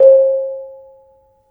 Bonang Barung Sl1 of Gamelan Kyai Parijata
BonangBarungSl1 of Gamelan Kyai Parijata.wav (129.43 KB)